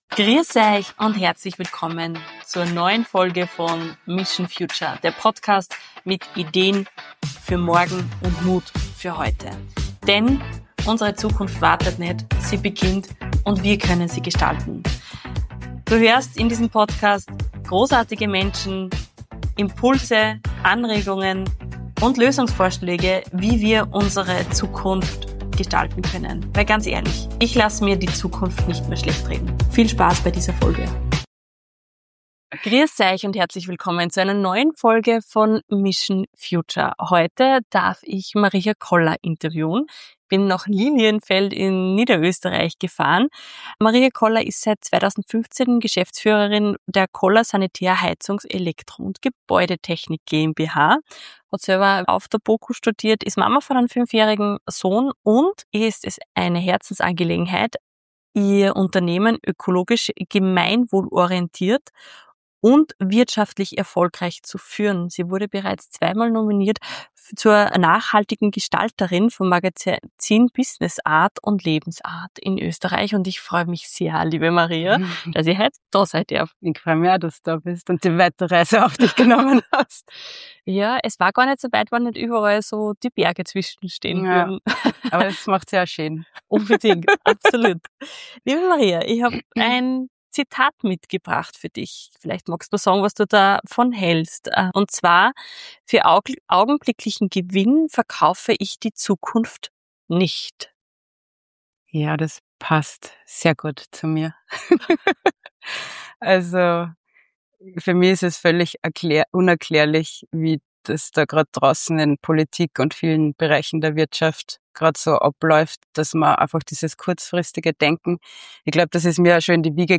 Sie zeigt, wie Purpose, Vertrauen und Intuition zu starken Teams, Innovation und Wachstum führen können. Ein Gespräch über Verantwortung, Zukunftsmut und die Frage: Welche Entscheidungen treffen wir heute für die Welt von morgen?